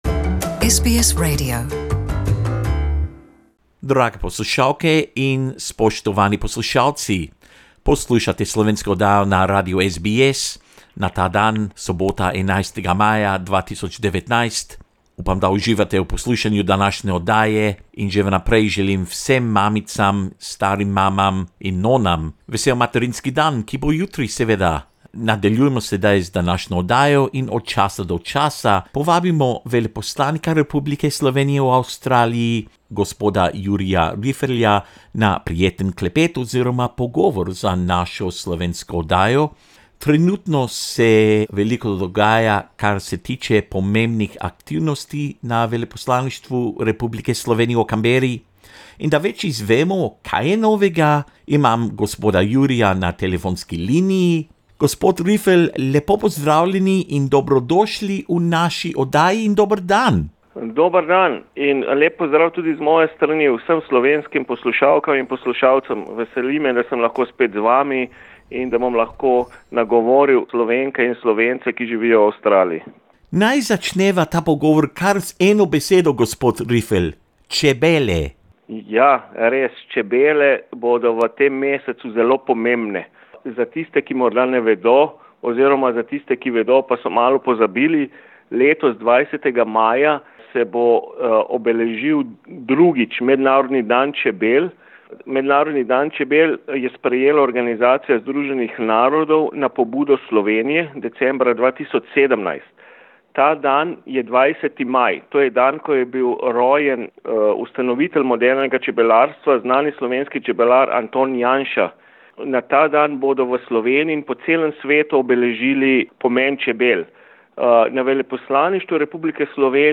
Jurij Rifelj, Ambassador of the Republic of Slovenia in Australia speaks about this year's World Bee Day, which will be on 20 May 2019. The Slovenian Ambassador in Australia also spoke about other events and activities currently taking place under the auspices of the Slovenian Embassy in Canberra.